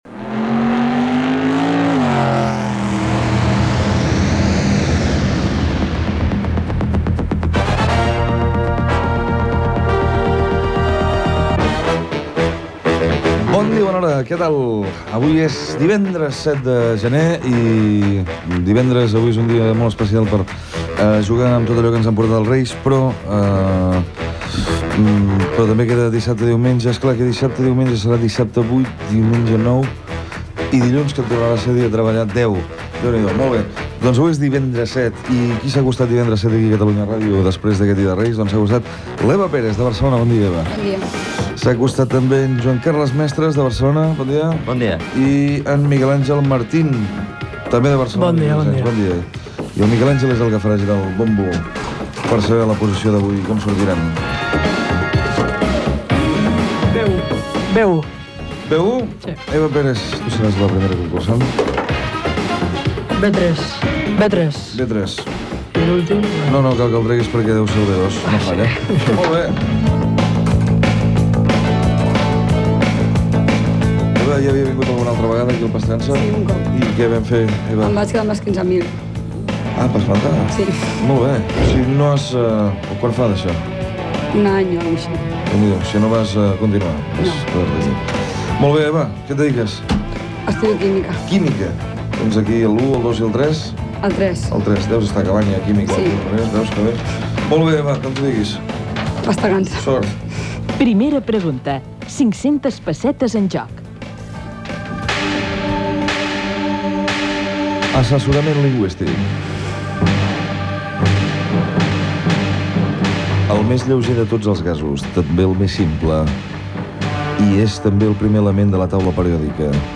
1c83eb1b4b85ab2d9b944bd169432eb4d4b18e43.mp3 Títol Catalunya Ràdio Emissora Catalunya Ràdio Cadena Catalunya Ràdio Titularitat Pública nacional Nom programa Pasta gansa Descripció Sintonia, presentació del programa i dels tres concursants. Sorteig de l'ordre d'intervenció i primeres preguntes del concurs.